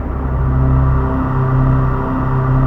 DM PAD2-39.wav